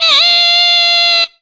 SOS SAX.wav